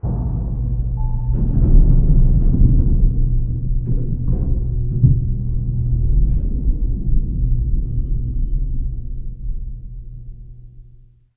Commotion7.ogg